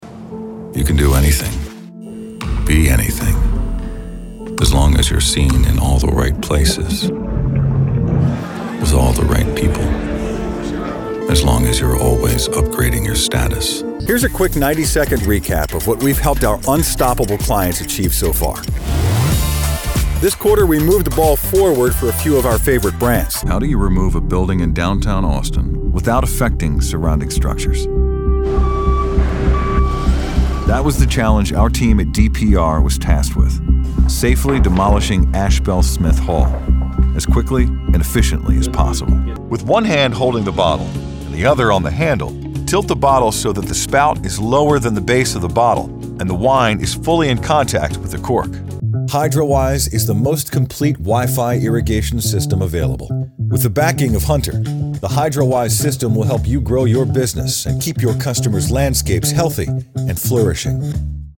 Rugged. Warm
Corporate Narration
Middle Aged